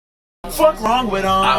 Horse Laugh - Bouton d'effet sonore